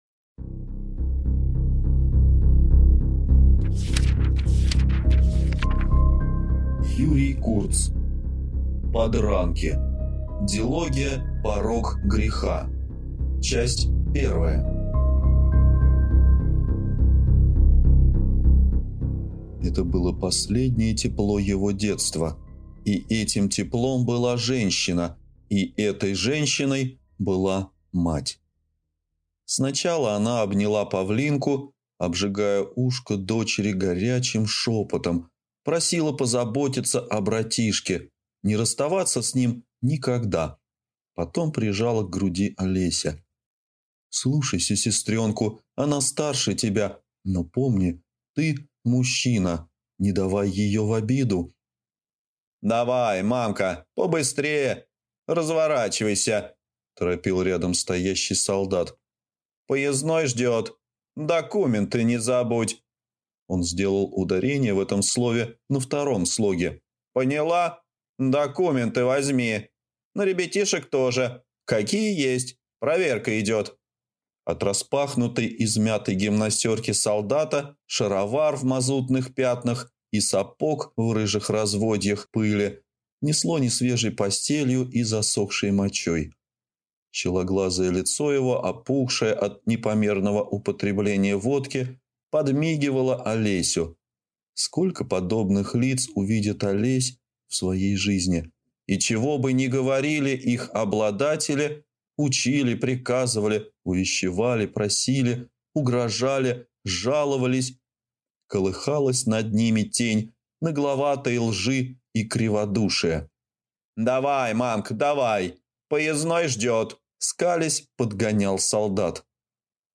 Аудиокнига Подранки. Дилогия «Порог греха». Часть 1 | Библиотека аудиокниг